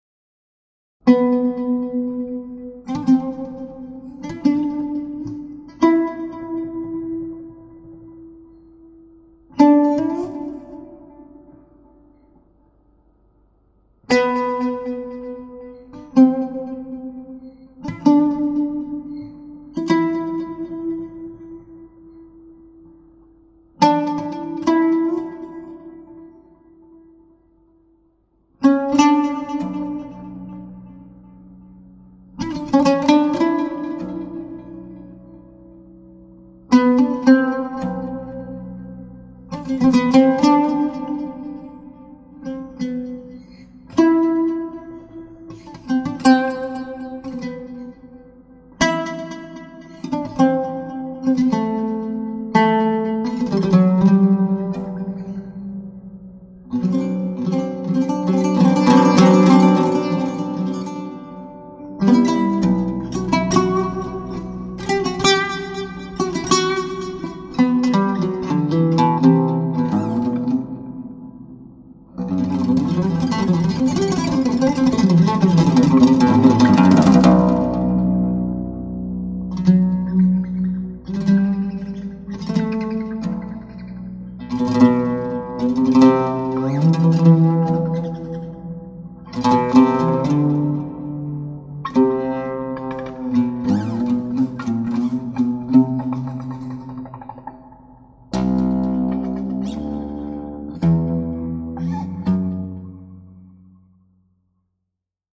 Genres: Improvisational Music on oud, Turkish Traditional.